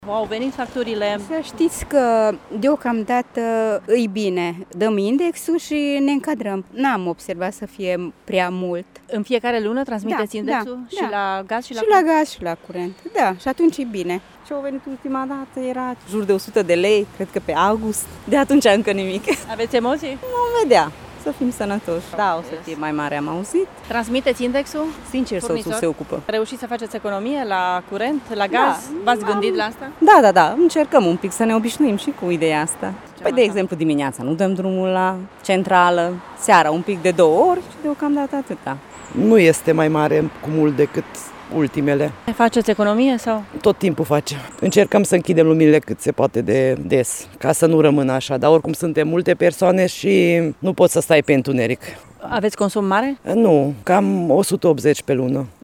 Târgumureșenii nu sunt, deocamdată, îngrijorați de facturile la energie, în schimb, recunosc că sunt atenți la consum: